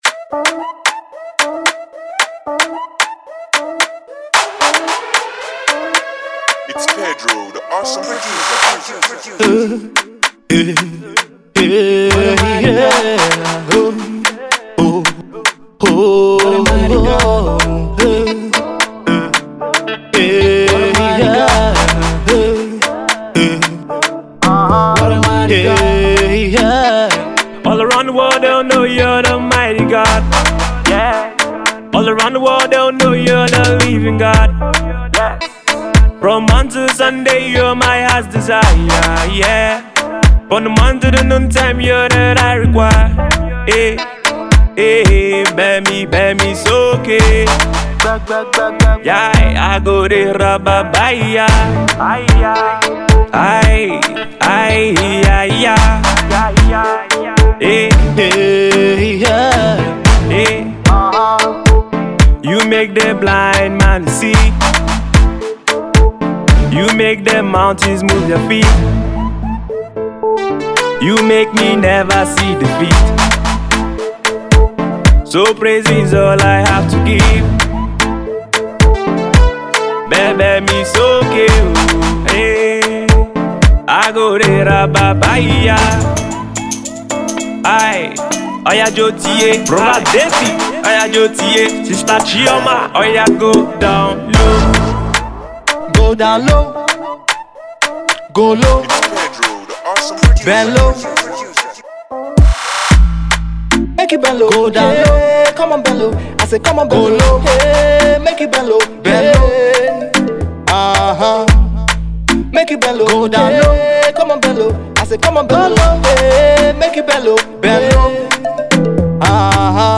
afrobeat rhythm